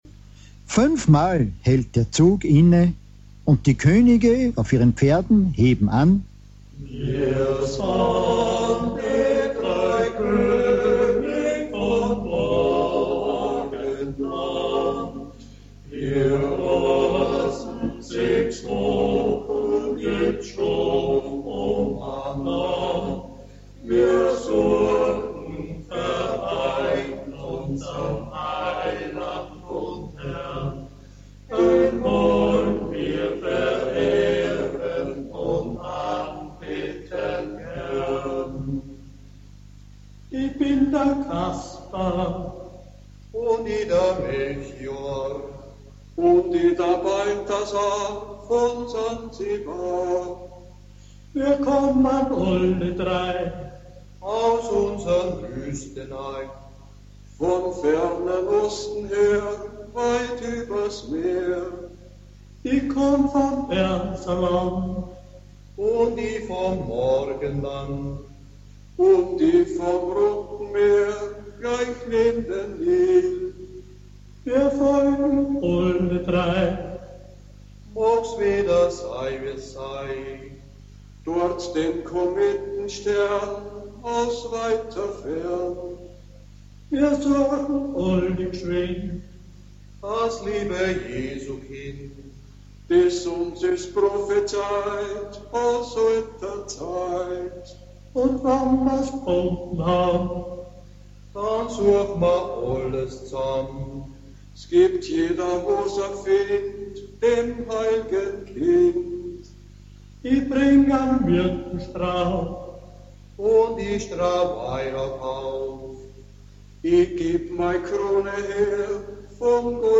Hier befinden sich die MP3-Dateien, Mitschnitte aus der Radiosendung Aufhorchen in Niederösterreich vom Jänner 2008.